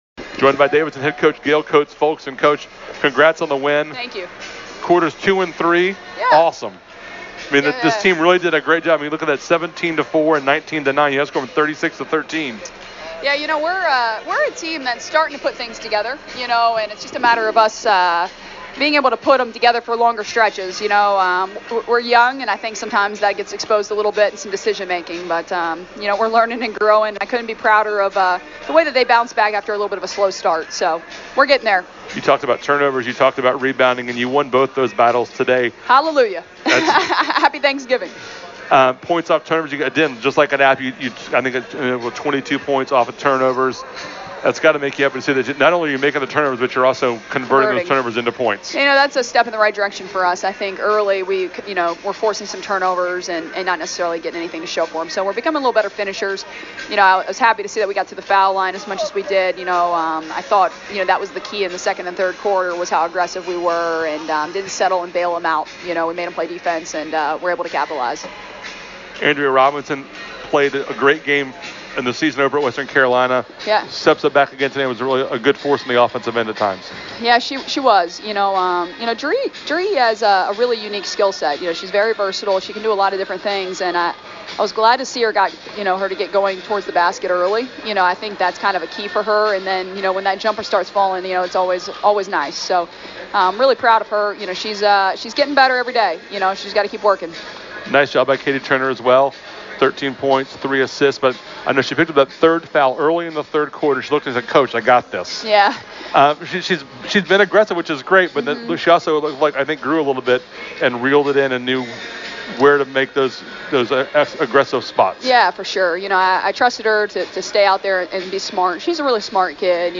UNC Asheville Post Game.mp3